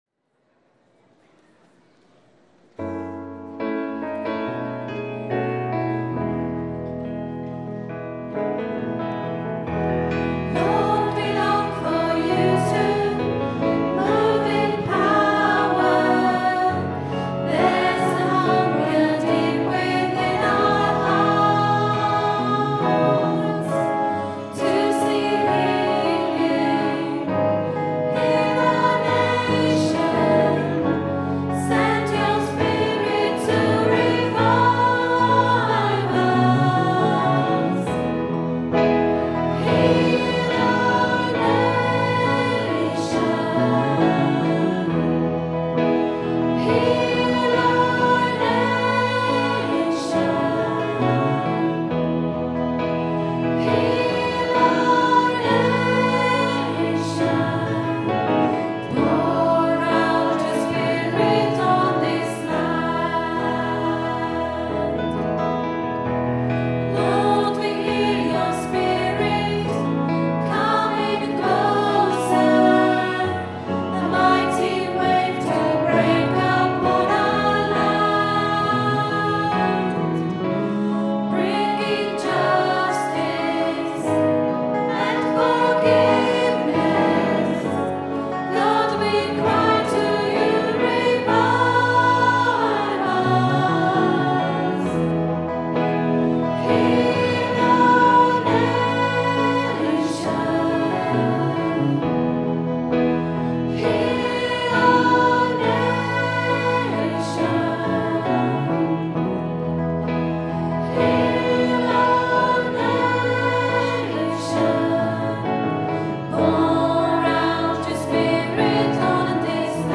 Recorded on our Zoom H4 digital stereo recorder at 10am Mass, Sunday 19th September 2010.